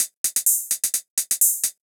Index of /musicradar/ultimate-hihat-samples/128bpm
UHH_ElectroHatB_128-05.wav